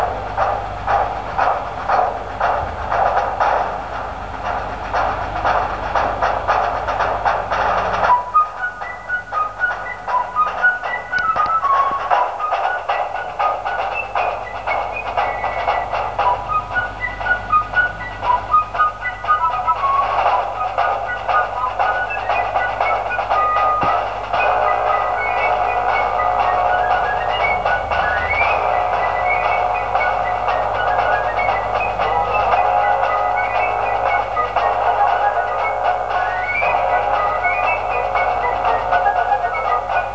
28k 56k 100k _____________ Thème musical